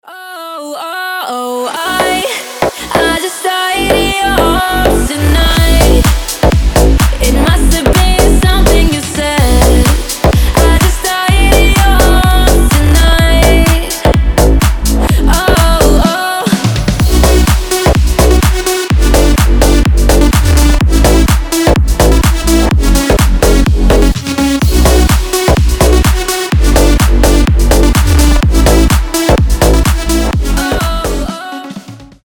громкие
энергичные
ремиксы
slap house